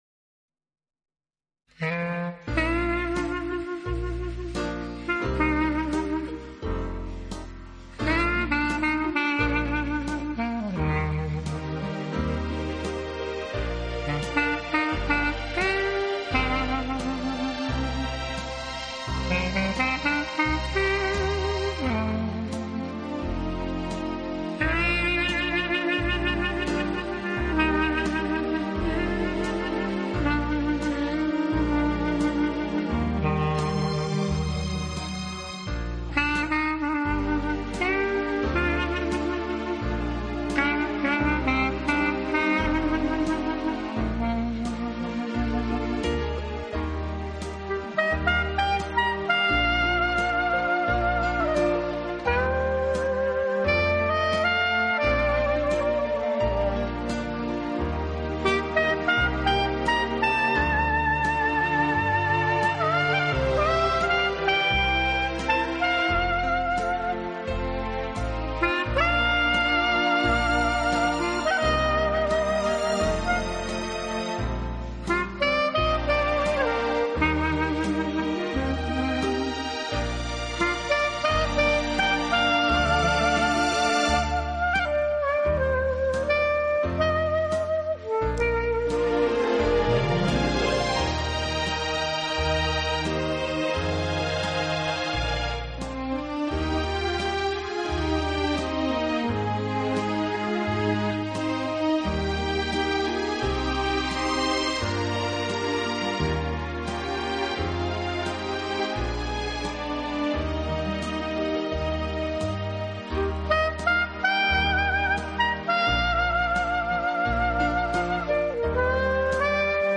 单簧管、弦乐